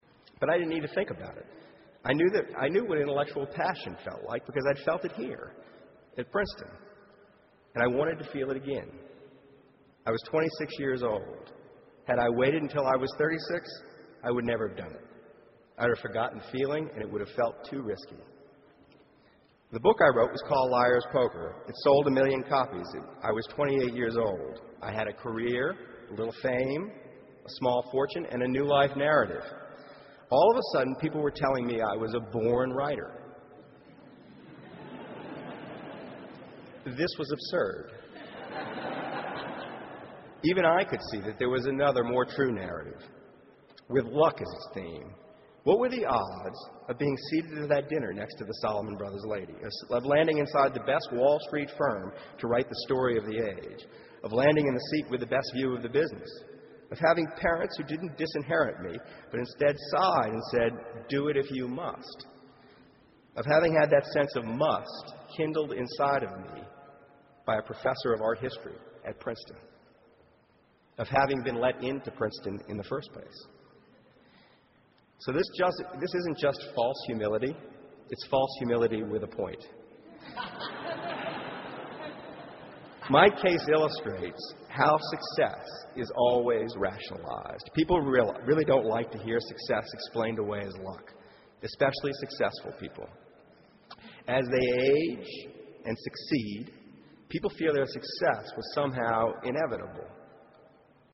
公众人物毕业演讲 第140期:2012年Michael Lewis普林斯顿大学(4) 听力文件下载—在线英语听力室